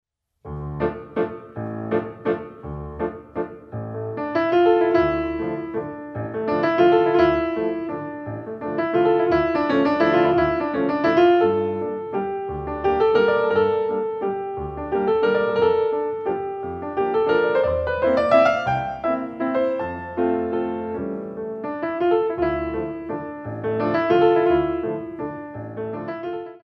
Pirouettes On a French Musette